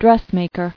[dress·mak·er]